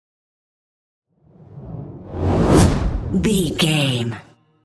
Whoosh fire ball
Sound Effects
Atonal
dark
intense
whoosh